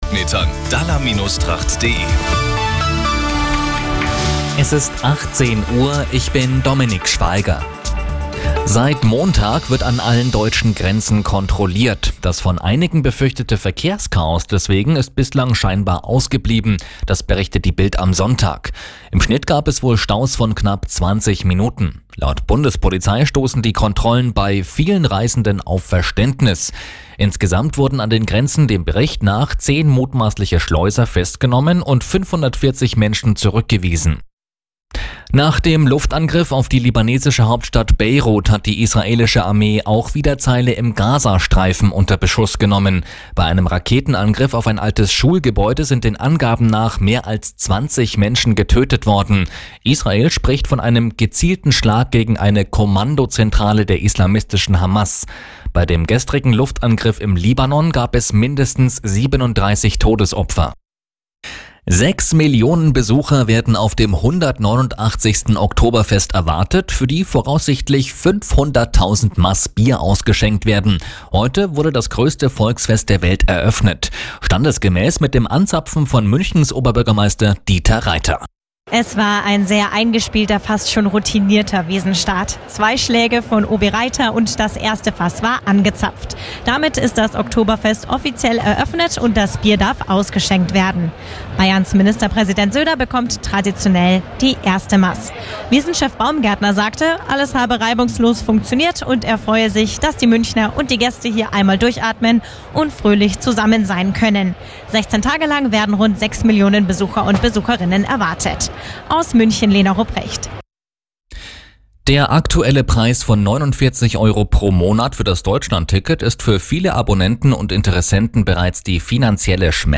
Die Arabella Nachrichten vom Samstag, 21.09.2024 um 17:59 Uhr - 21.09.2024